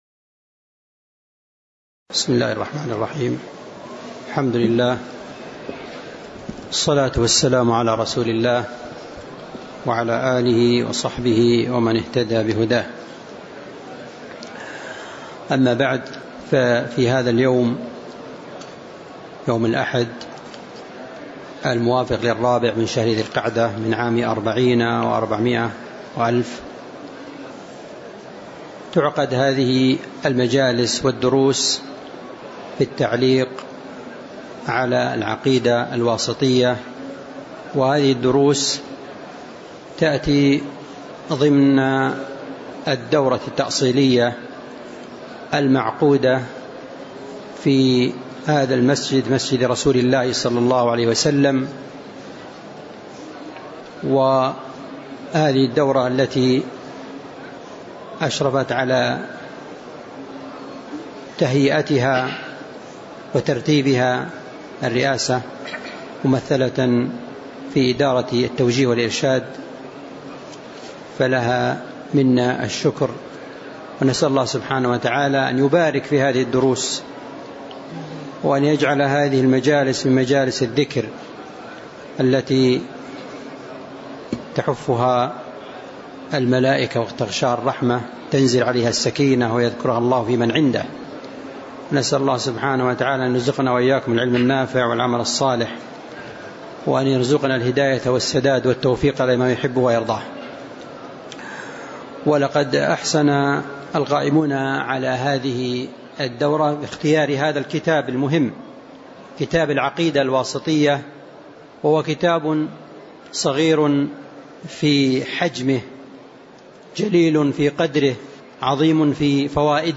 تاريخ النشر ٤ ذو القعدة ١٤٤٠ هـ المكان: المسجد النبوي الشيخ